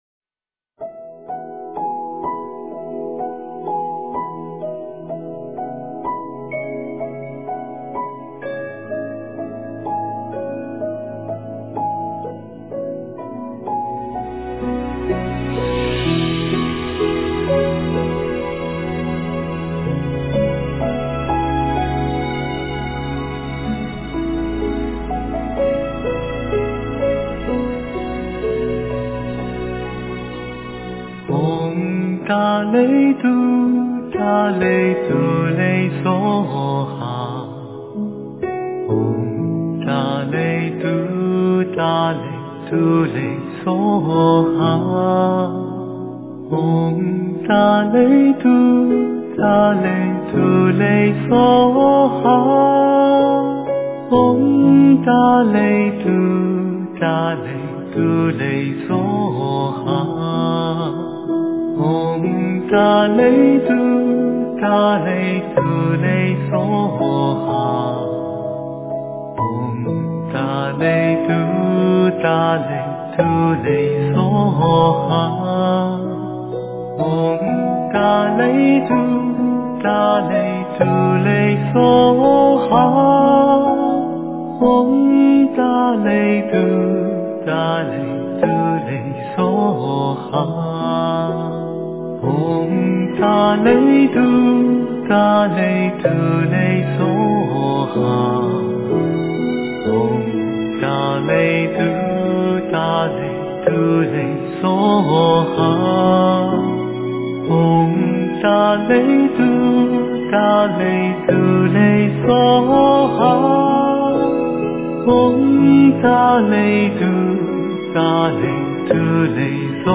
诵经
佛音 诵经 佛教音乐 返回列表 上一篇： 炉香赞 下一篇： 回向文 相关文章 佛陀 佛陀--佛教音乐...